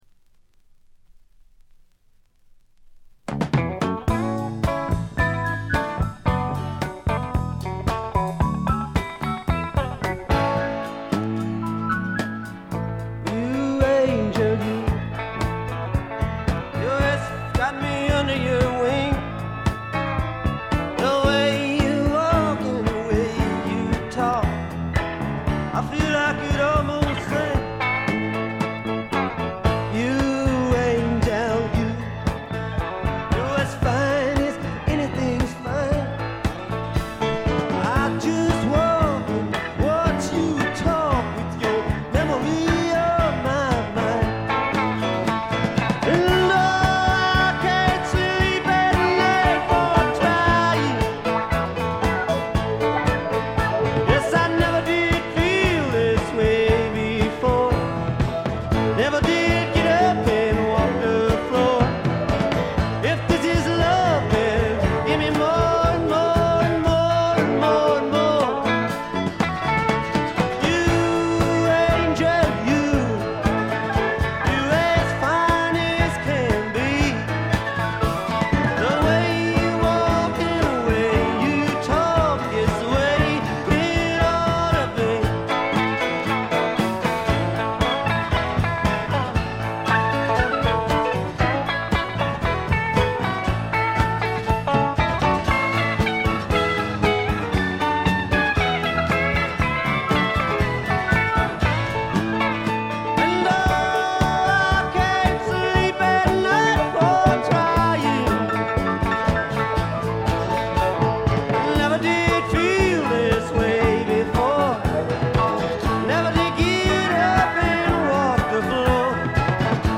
ほとんどノイズ感無し。
試聴曲は現品からの取り込み音源です。
guitar, harmonica, piano, vocals
guitars, bass
keyboards, organ, piano, accordion, saxophones
piano, keyboards, drums
bass, violin
drums, mandolin